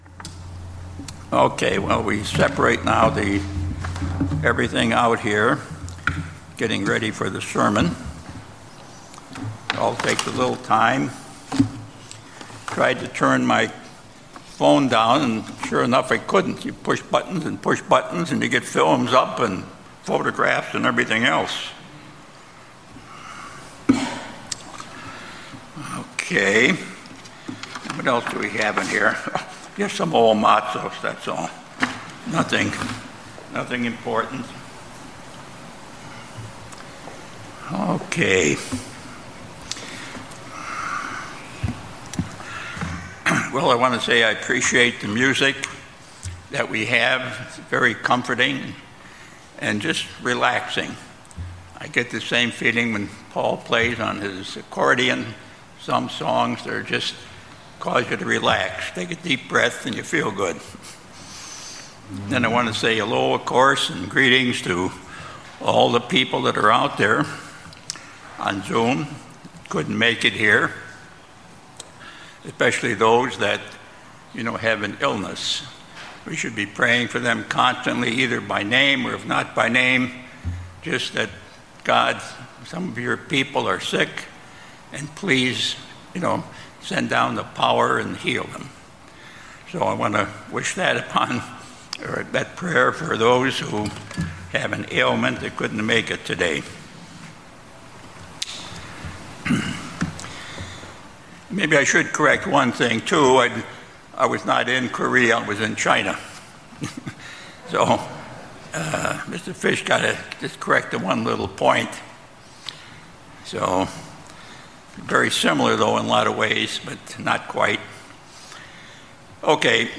Sermons
Given in Los Angeles, CA Bakersfield, CA